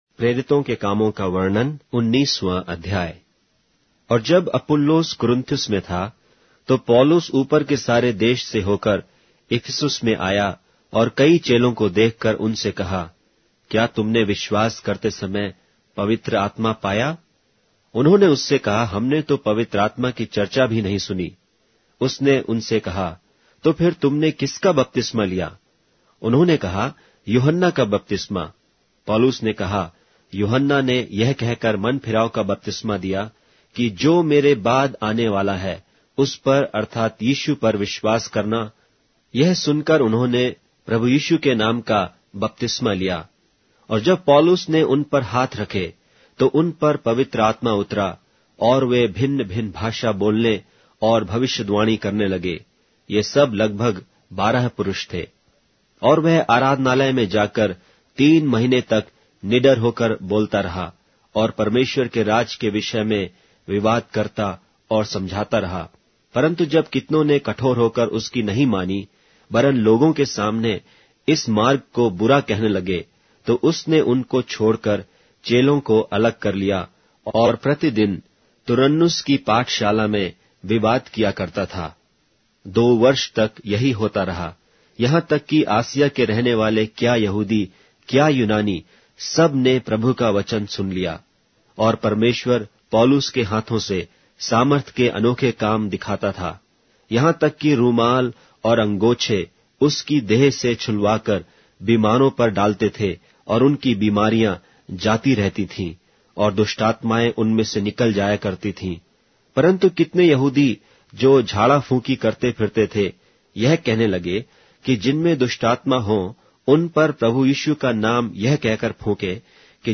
Hindi Audio Bible - Acts 25 in Hov bible version